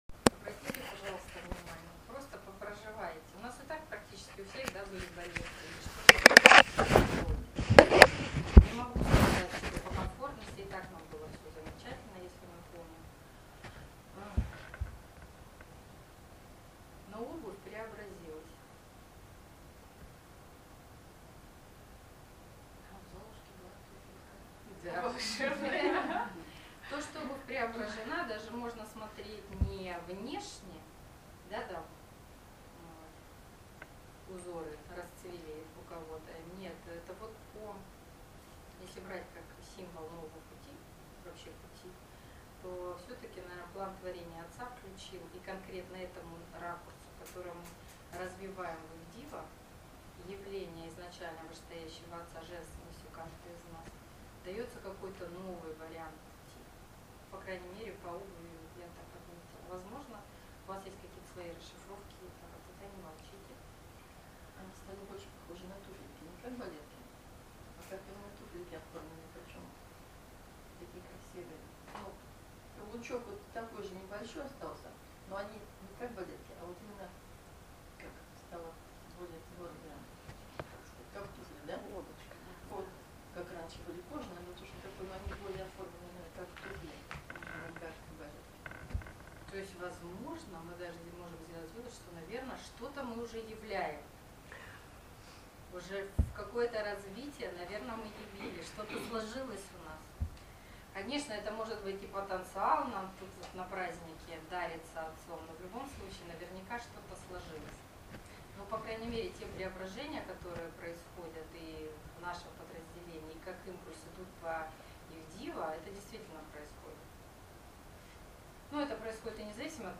Занятие «Идивная Сударыня» 2017-01-09 Севастополь